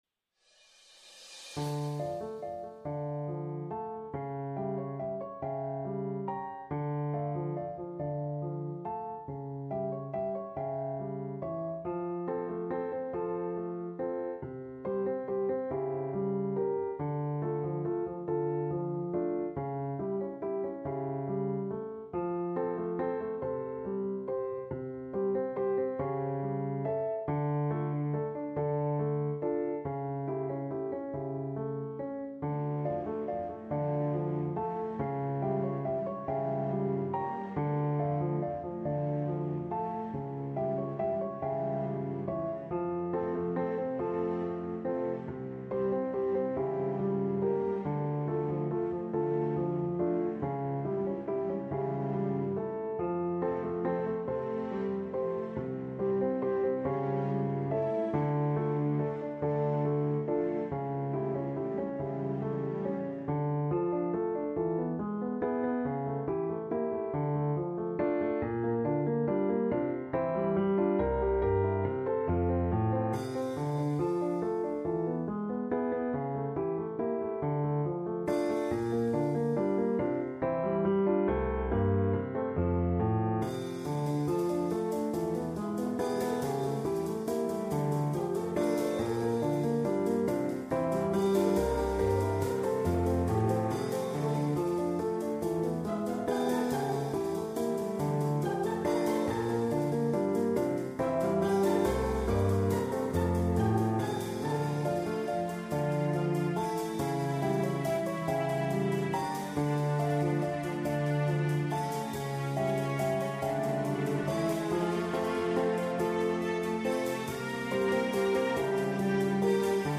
Συζήτηση για άλλη μια σπουδαία δουλειά του.